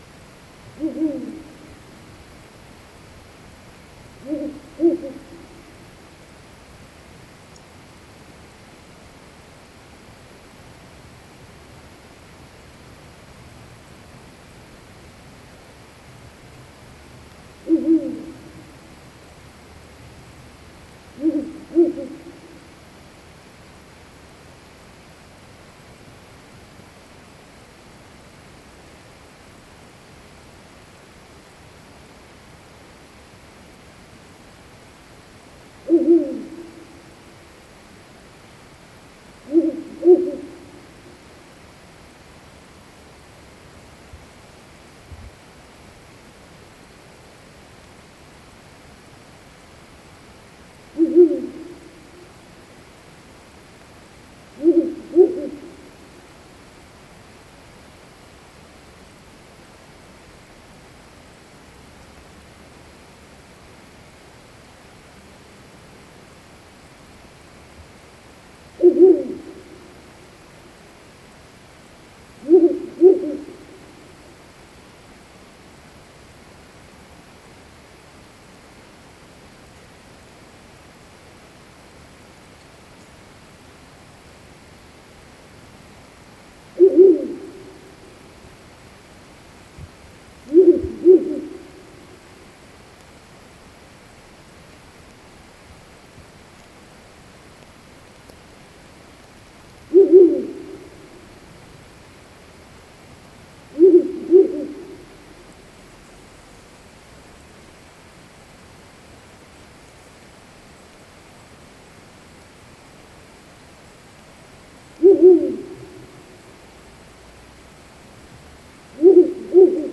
длиннохвостая неясыть, Strix uralensis
СтатусПара в подходящем для гнездования биотопе